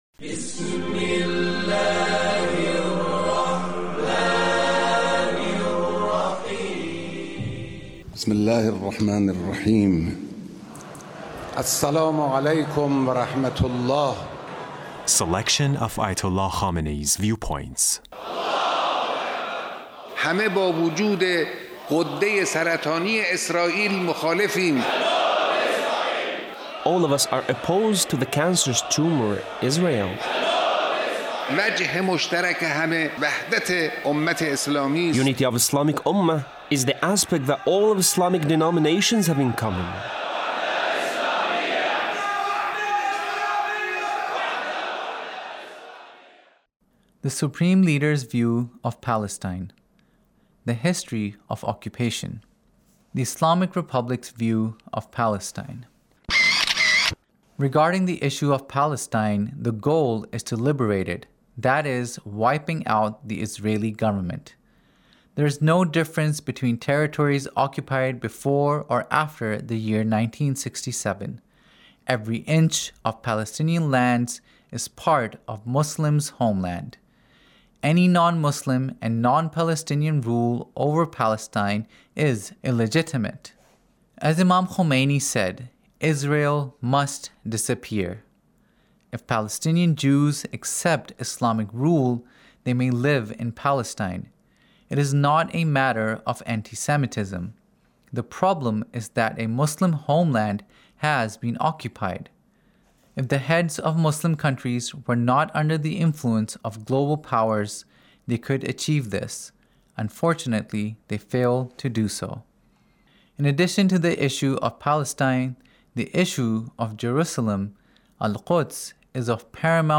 Leader's Speech in a Meeting with the Three Branches ofGovernment Repentance